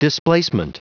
Prononciation du mot displacement en anglais (fichier audio)
Prononciation du mot : displacement